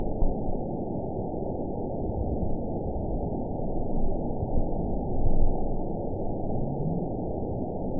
event 920812 date 04/09/24 time 23:40:23 GMT (1 year, 2 months ago) score 9.42 location TSS-AB03 detected by nrw target species NRW annotations +NRW Spectrogram: Frequency (kHz) vs. Time (s) audio not available .wav